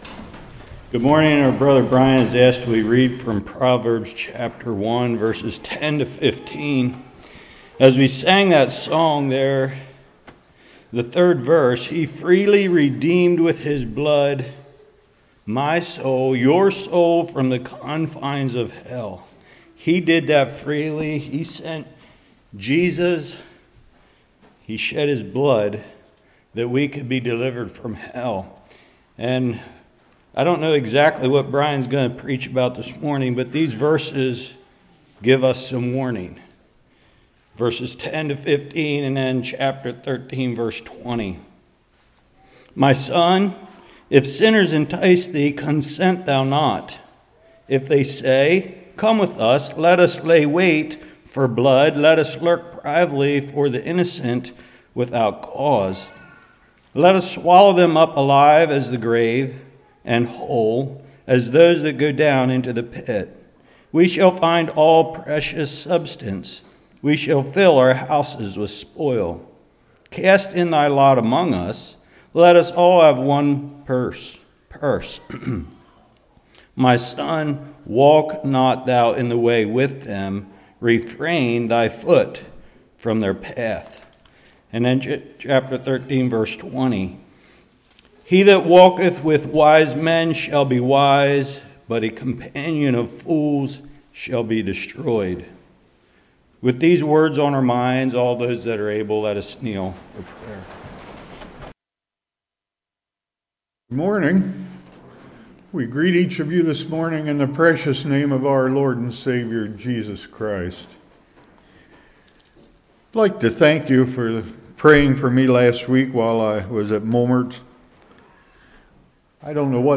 13:20 Service Type: Morning We need to be careful of influences in our life.